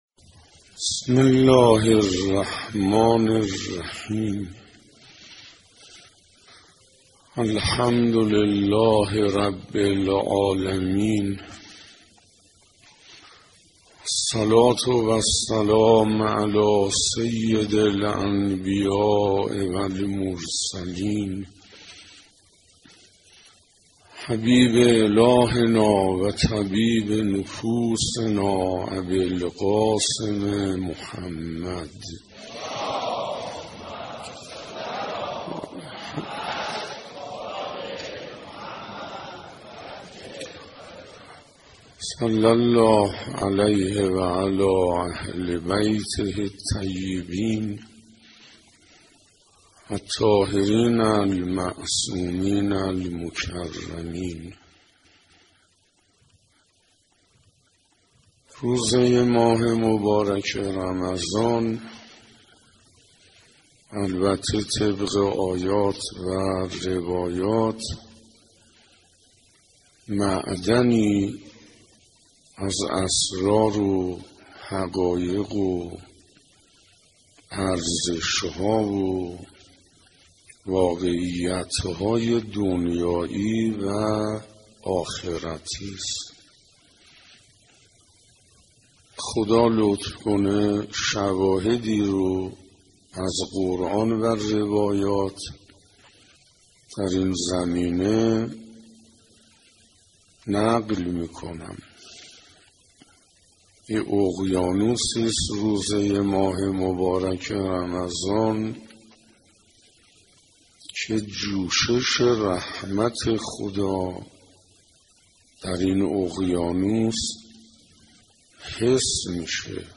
دانلود دومین جلسه از بیانات آیت الله حسین انصاریان با عنوان «ارزشهای ماه رمضان»